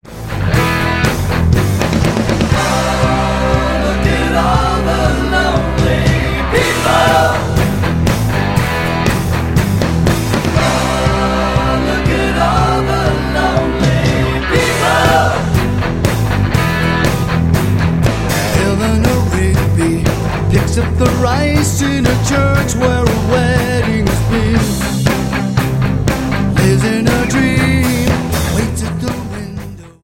guitar driven cover
with a rocking bassline